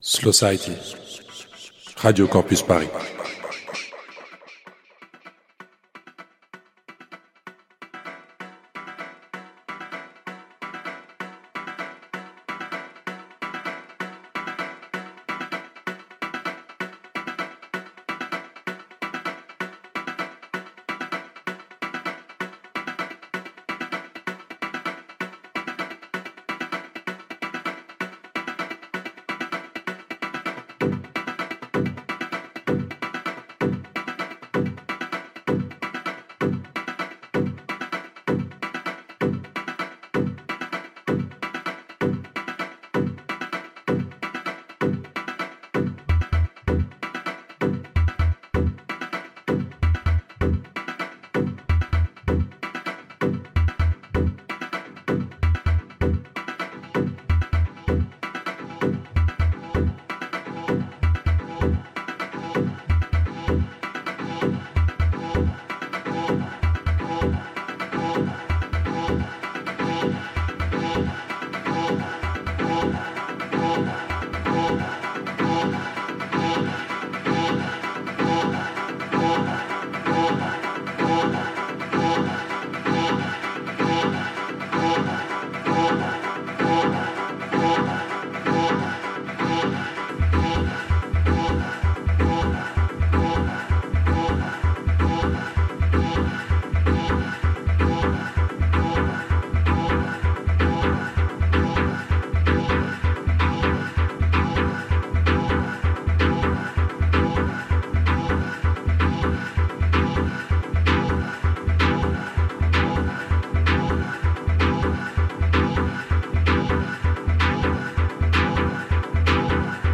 We had the pleasure to have them in the show this week !